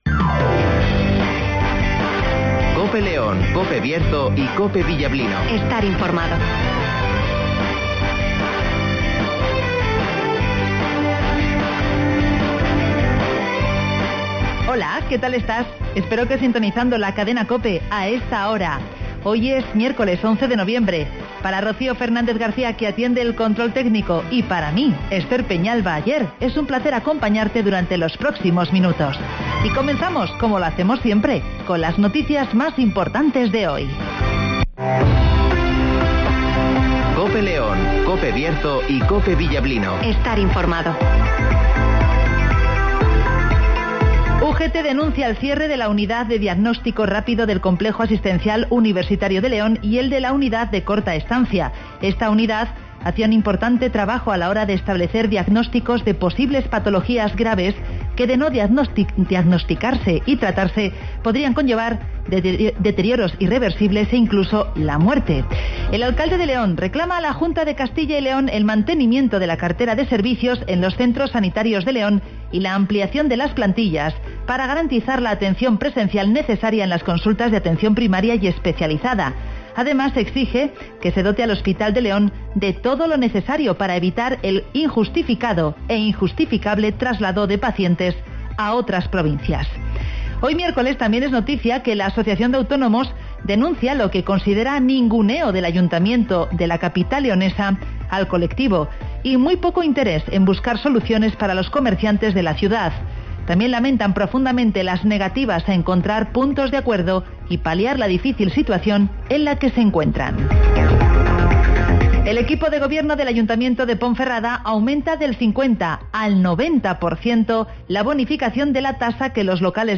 Avance informativo, El Tiempo (Neucasión) y Agenda (Carnicerias Lorpy)